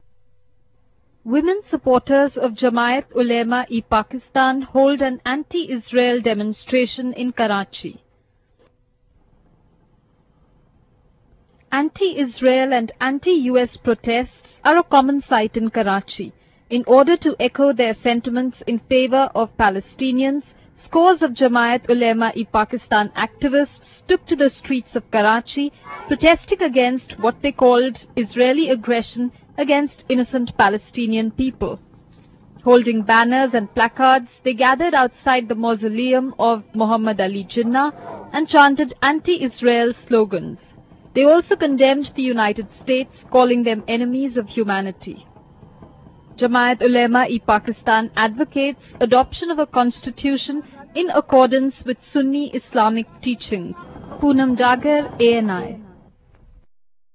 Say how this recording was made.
In video: Women supporters of Jamiat Ulema-e-Pakistan hold an anti-Israel demonstration in Karachi.